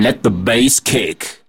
For this exercise vocal samples work particularly well. I have chosen this sample here: